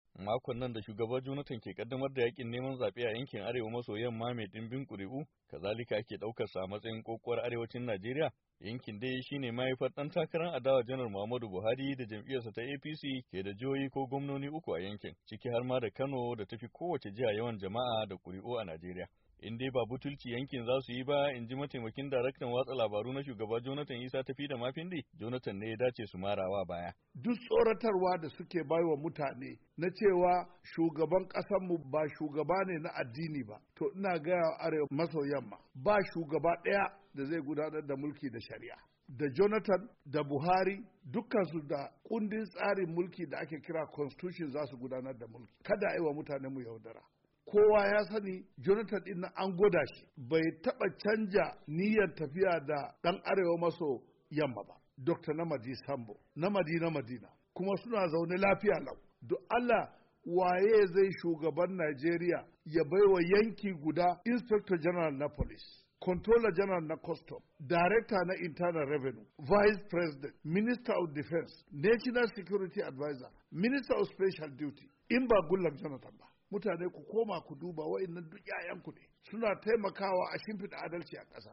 Ga rahoto.